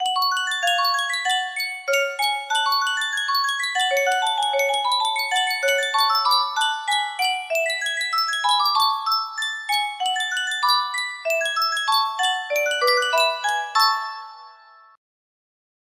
Yunsheng Custom Tune Music Box - Bach Invention No. 13 music box melody
Full range 60